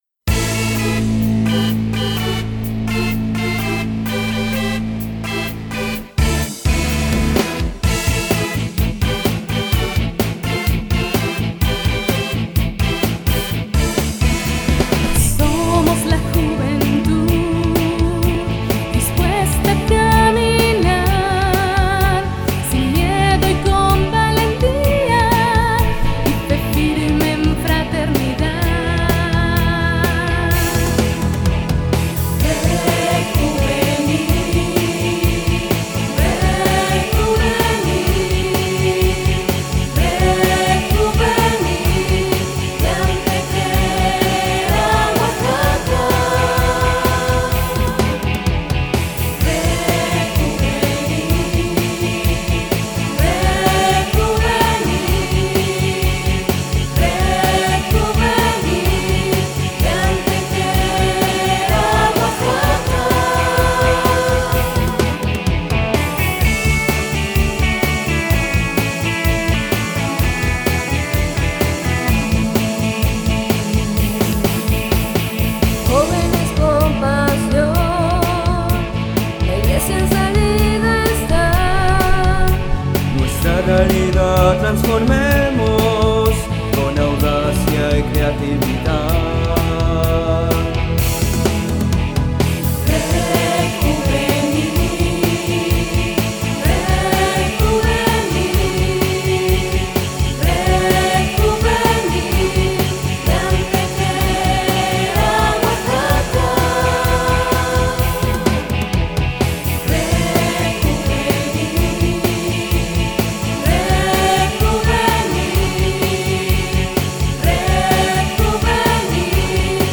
HIMNO-RED-JUVENIL.mp3